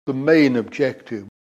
For comparison, here are examples of objÉctive used by natives: